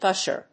音節gúsh・er 発音記号・読み方
/ˈgʌʃɝ(米国英語), ˈgʌʃɜ:(英国英語)/